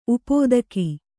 ♪ upōdaki